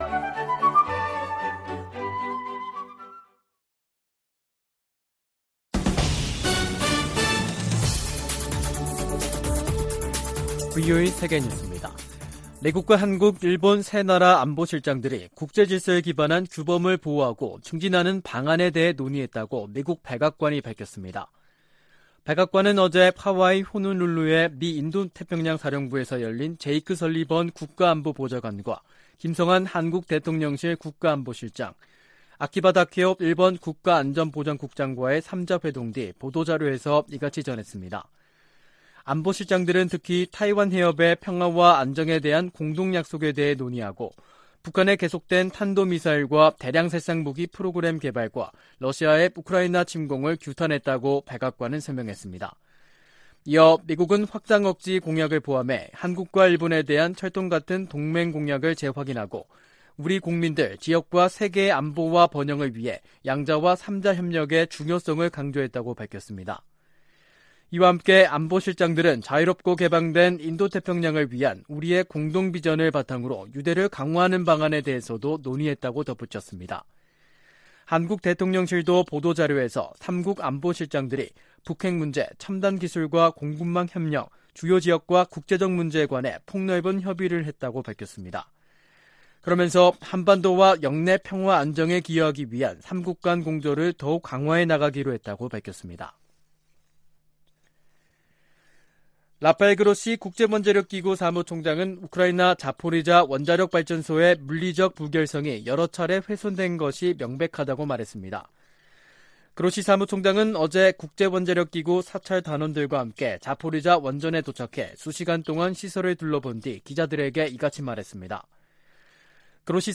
VOA 한국어 간판 뉴스 프로그램 '뉴스 투데이', 2022년 9월 2일 2부 방송입니다. 미국과 한국, 일본 안보실장들이 하와이에서 만나 북한 미사일 프로그램을 규탄하고 타이완해협 문제 등을 논의했습니다. 미국과 한국 간 경제 협력을 강화하기 위한 미 의원들의 움직임이 활발해지고 있습니다. 미국 검찰이 북한의 사이버 범죄 자금에 대한 공식 몰수 판결을 요청하는 문건을 제출했습니다.